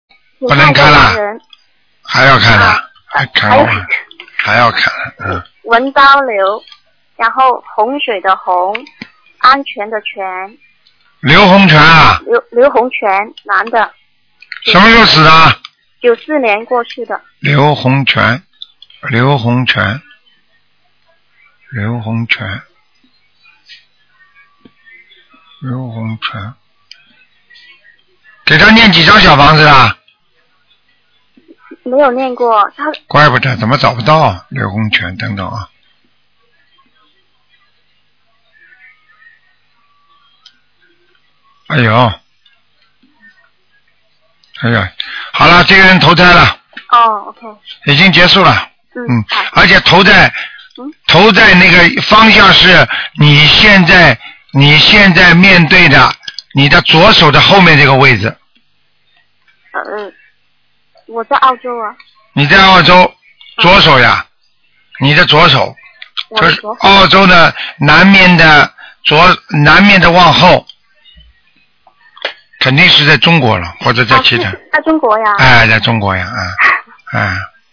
目录：2013年01月_剪辑电台节目录音集锦